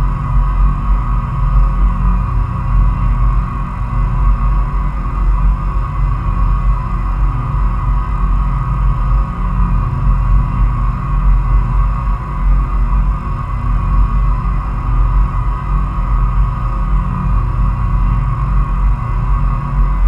turbine01_loop.wav